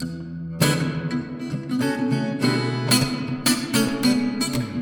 Ahora procesaremos ese Audio en Audacity con una reverberación.
Con estos parametros solo hacemos exagerado el efecto para encontrar la diferencia.
Este es nuestro Audio procesado.
guitarraprocesada.mp3